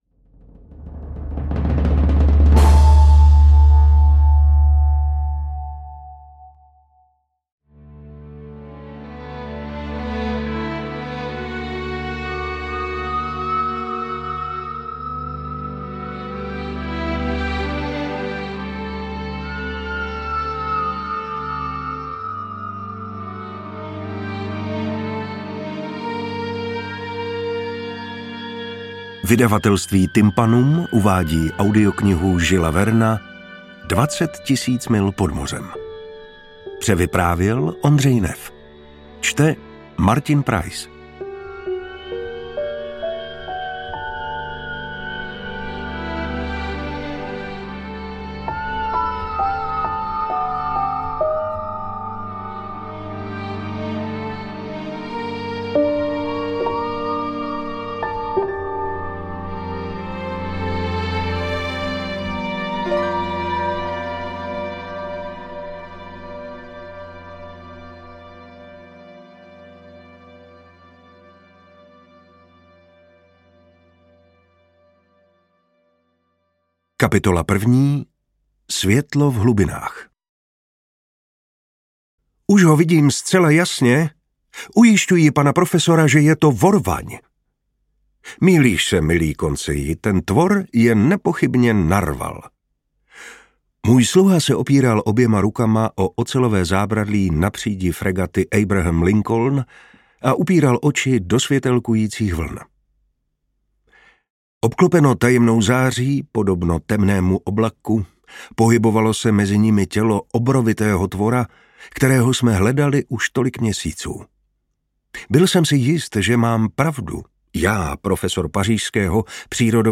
Interpret:  Martin Preiss